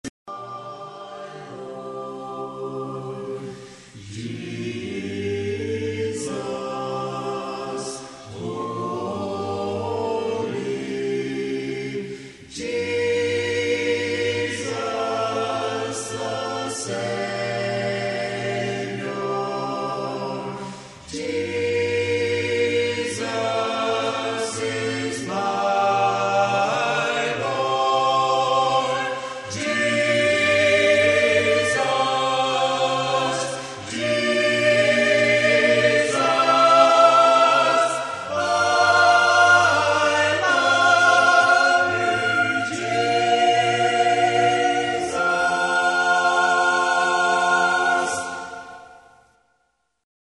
praise